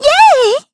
Xerah-Vox_Happy3_kr.wav